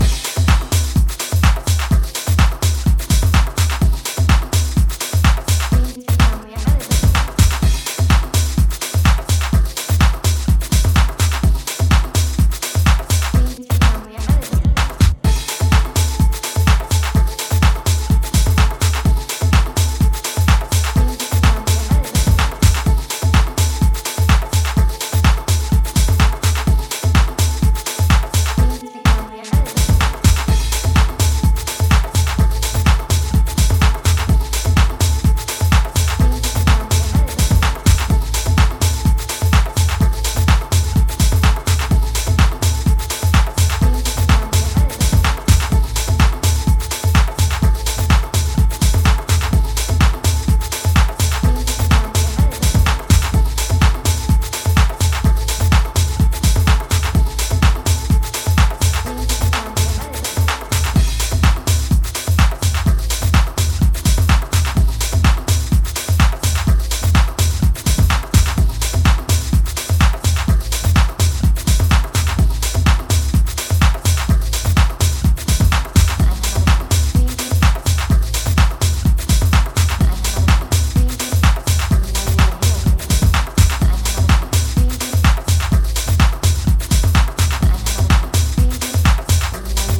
celebratory anthem
infectious and uplifting dance music
happy and uplifting vocal summer tune